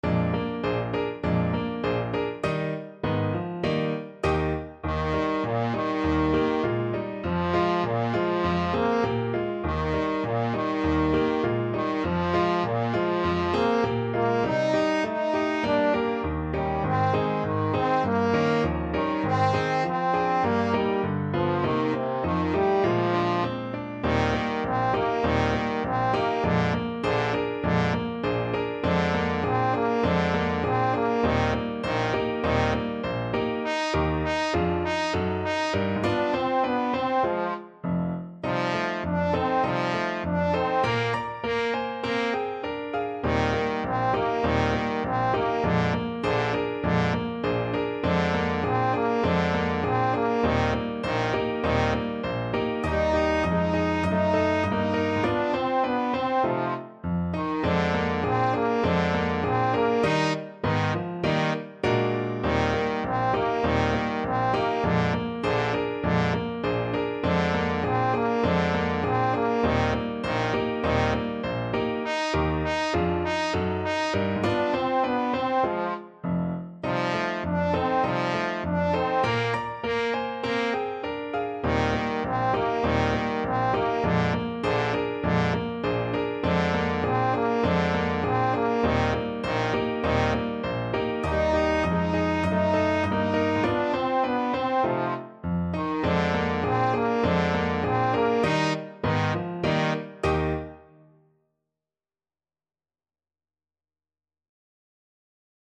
2/2 (View more 2/2 Music)
Moderato =c.100
Pop (View more Pop Trombone Music)